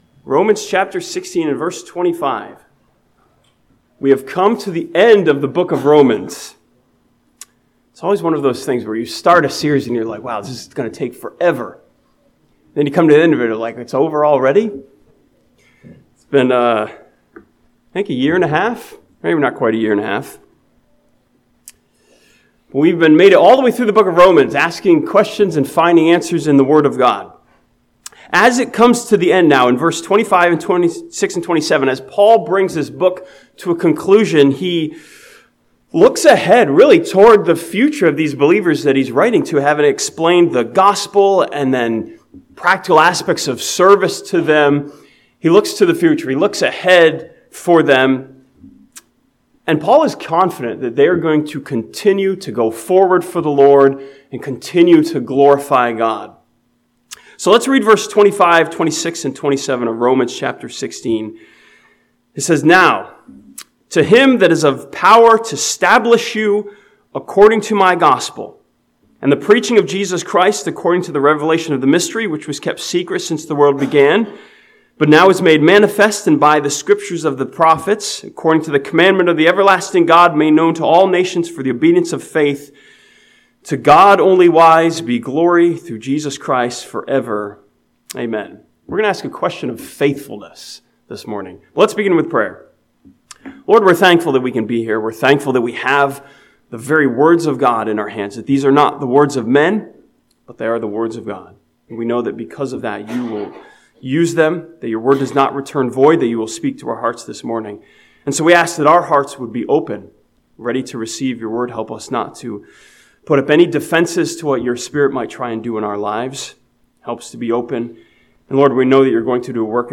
This sermon from Romans chapter 16 challenges us with a question of faithfulness: "how can we endure?"